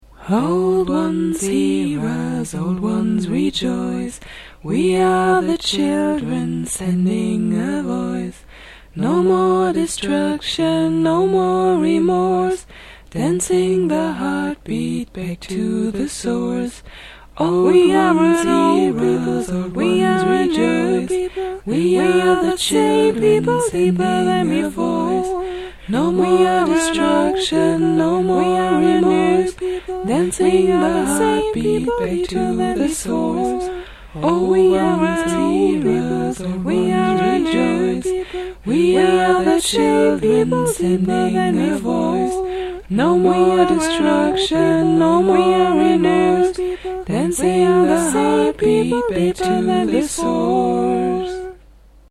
Chants und Hexenlieder
Wichtig hierbei ist immer, dass wir tief und frei aus dem Körper heraus singen, ohne uns Gedanken darüber zu machen, ob wir nun einmal einen Ton treffen oder nicht.
Chants hingegen sind sehr kurze Stücke, die immer wieder wiederholt werden.